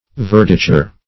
Search Result for " verditure" : The Collaborative International Dictionary of English v.0.48: Verditure \Ver"di*ture\ (?; 135), n. [Cf. Verditer .] The faintest and palest green.